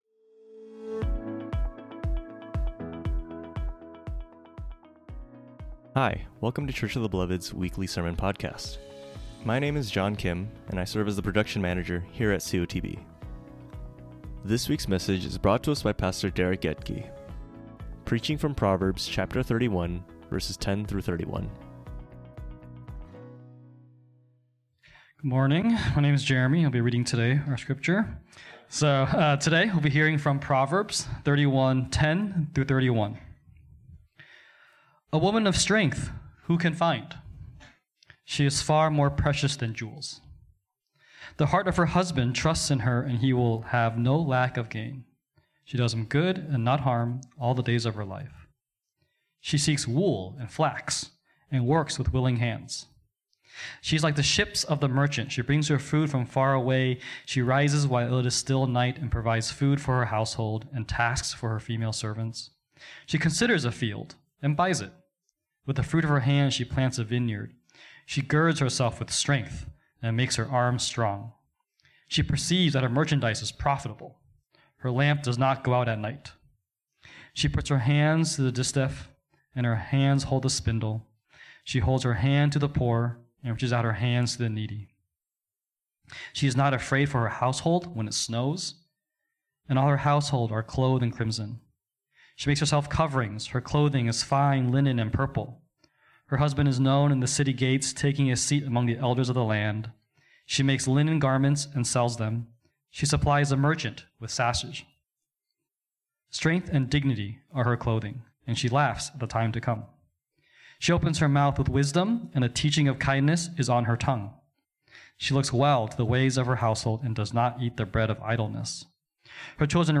The Firm Foundation of Wisdom | The Sacred Shift | Church of the Beloved